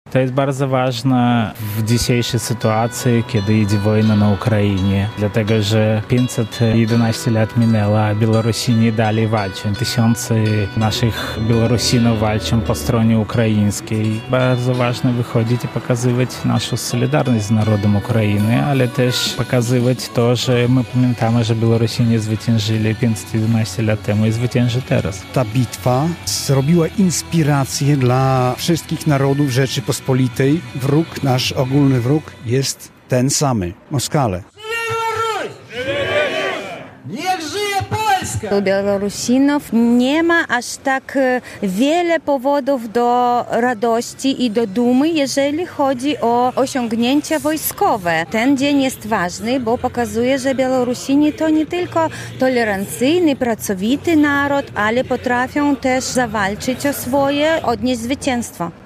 511. rocznicy Bitwy pod Orszą - akcja przed Konsulatem Białorusi w Białymstoku